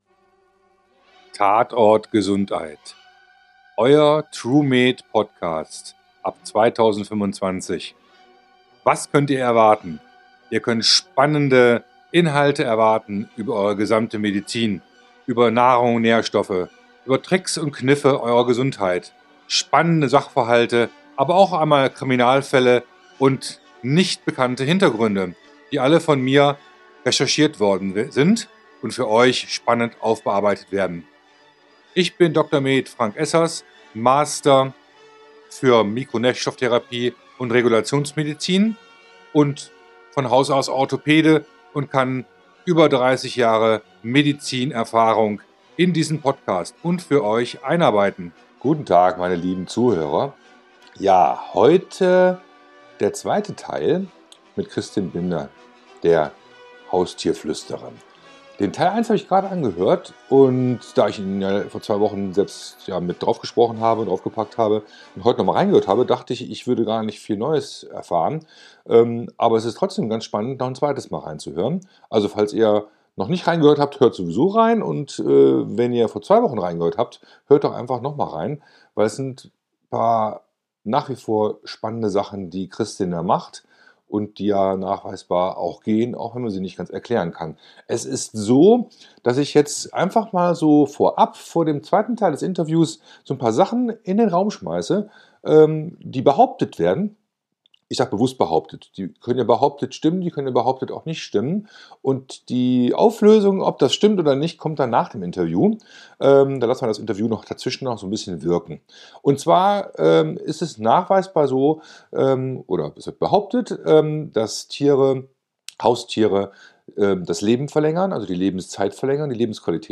Tier-Mensch Mensch-Tier-Achse, Eine tiefe Gesundheitsverbindung: Halter-Tier, Tier-Halter Experten-Interview Teil2, #40